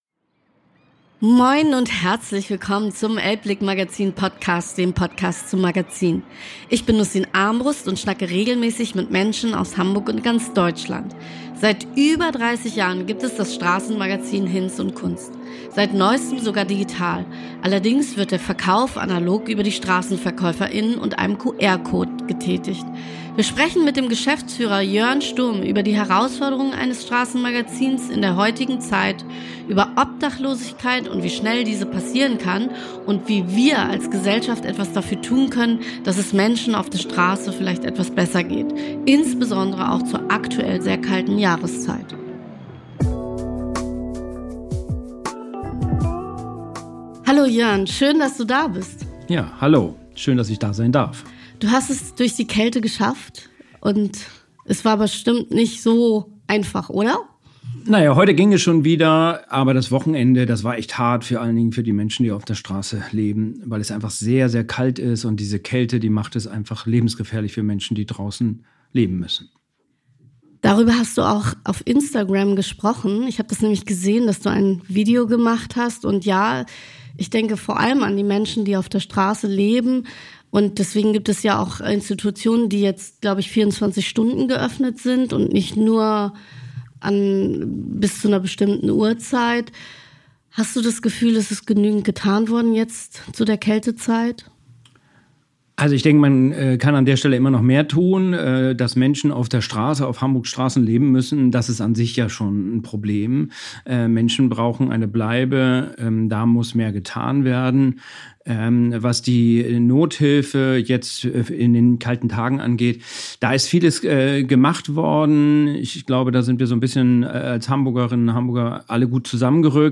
Ein spannendes Gespräch mit einer etwas anderen Sicht auf Menschen, die auf der Straße leben.